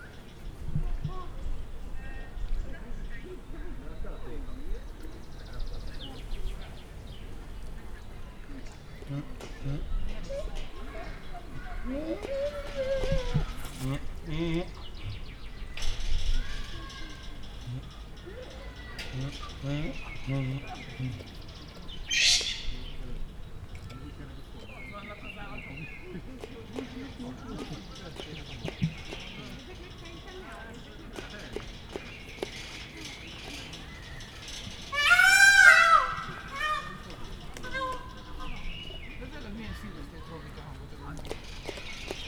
szamar_emu_aranyfacan_pava_cardioid00.42.WAV